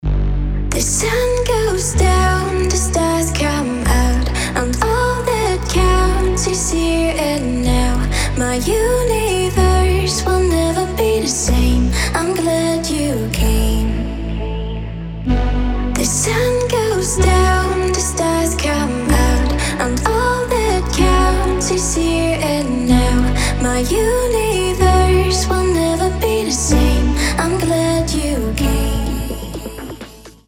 мелодичные
спокойные
красивый женский голос
Melodic